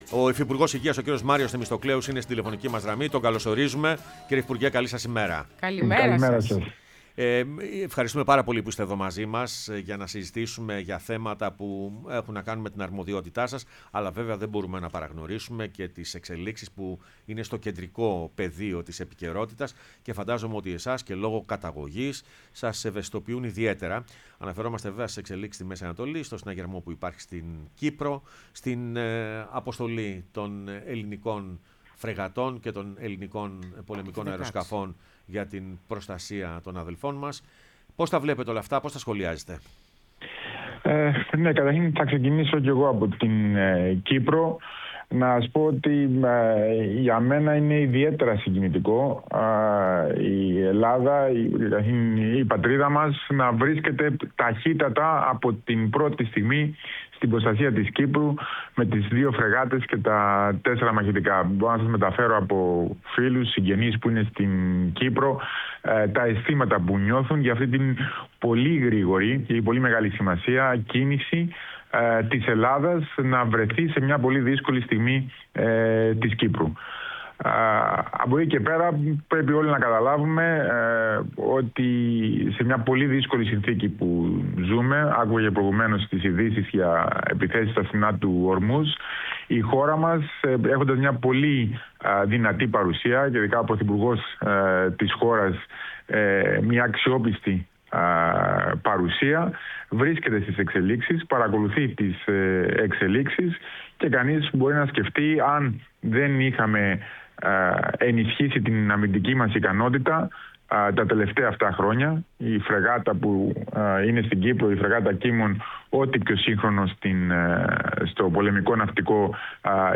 Ο Μάριος Θεμιστοκλέους, υφυπουργός Υγείας, μίλησε στην εκπομπή Πρωινές Διαδρομές